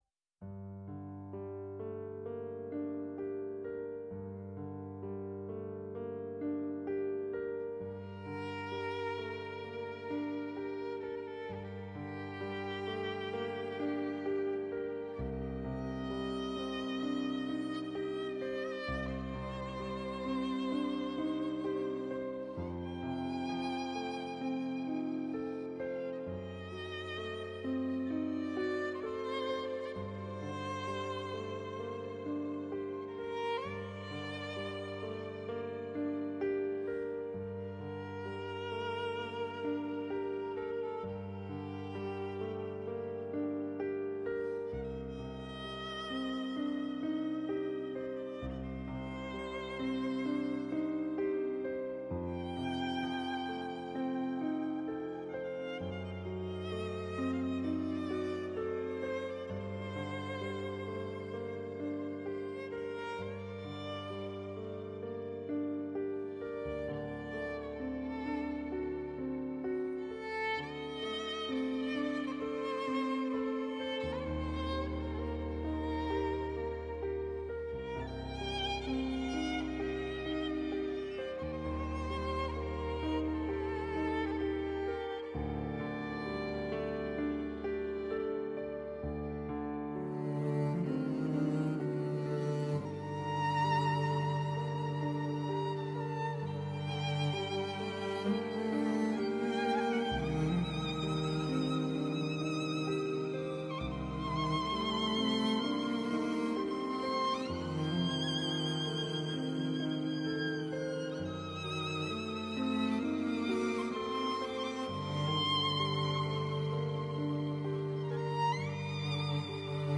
Η μουσική συνοδεύει, εκφράζοντας το «ανείπωτο».